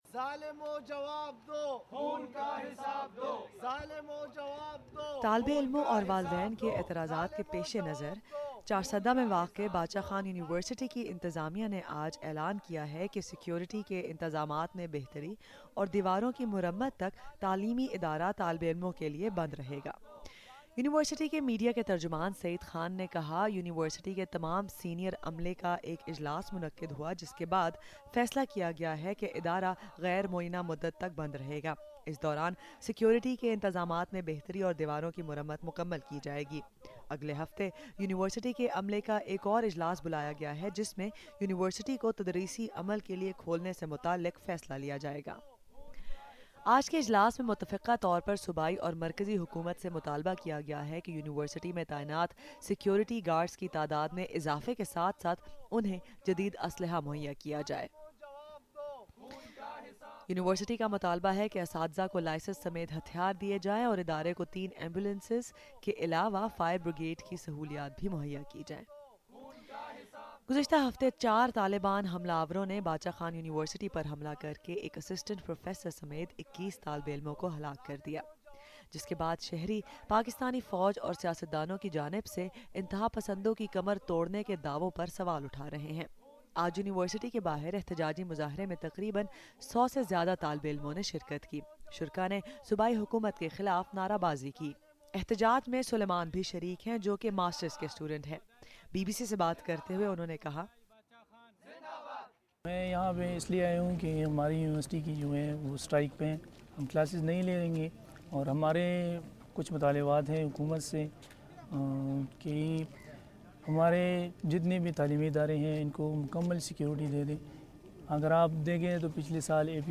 حملے میں متاثر ہونے والے طالب علموں سے بات کی۔